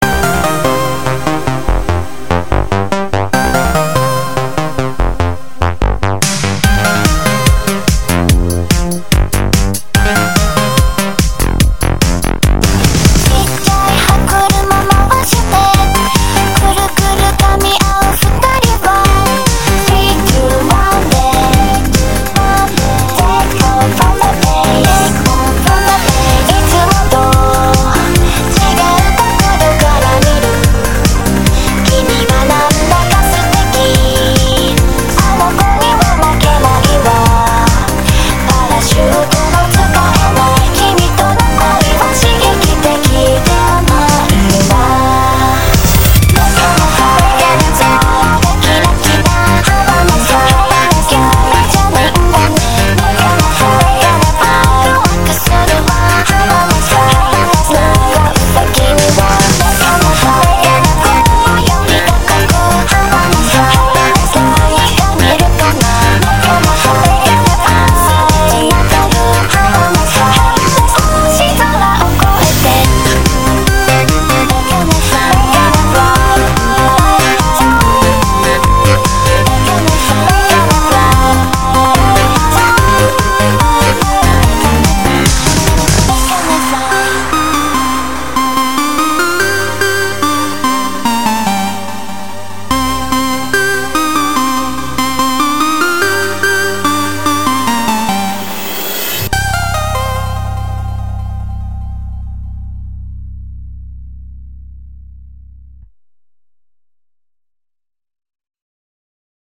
BPM145
Audio QualityPerfect (High Quality)
Commentaires[J-POP]